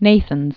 (nāthənz), Daniel 1928-1999.